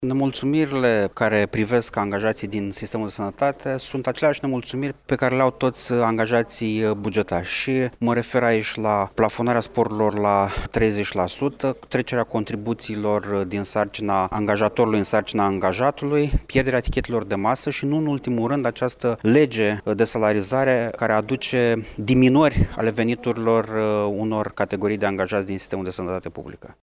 Anunţul a fost făcut, astăzi, în şedinţa Comisiei de Dialog social de la Iaşi.